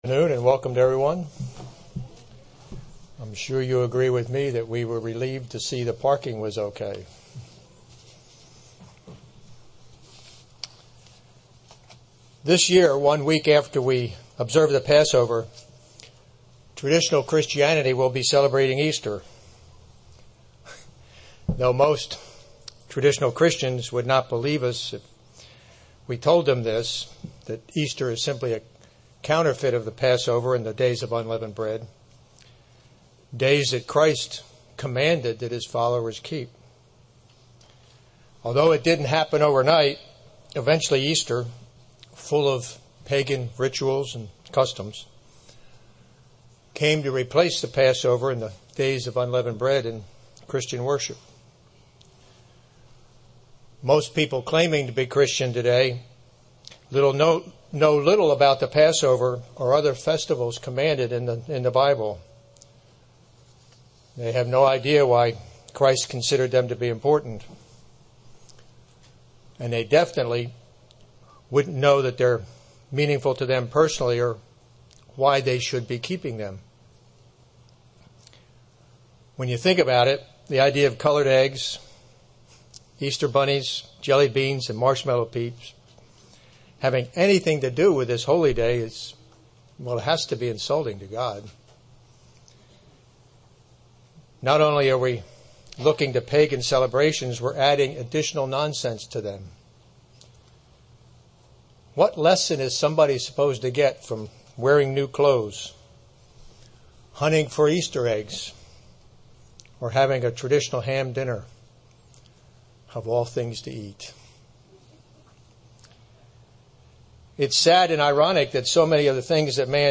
Sermons
Given in Tampa, FL